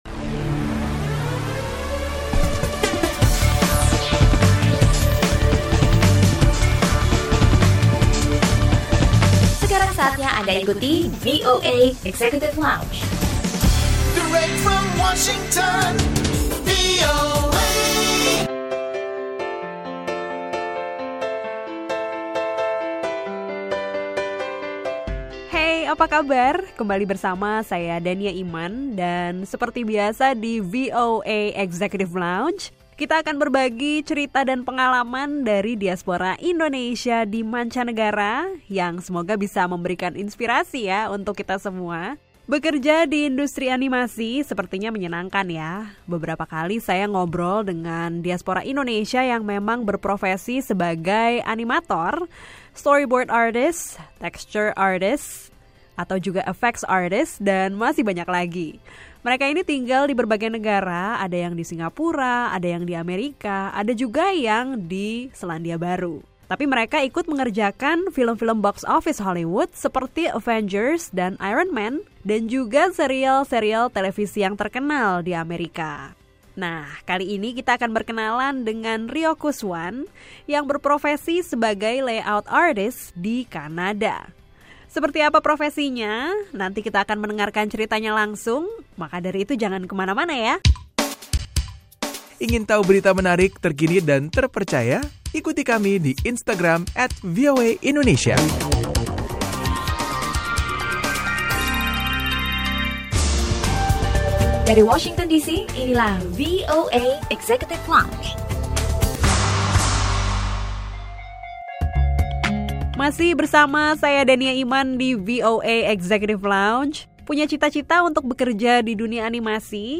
Obrolan bersama diaspora Indonesia yang berprofesi sebagai seorang animator dan Layout Supervisor untuk berbagai proyek animasi, seputar pengalamannya.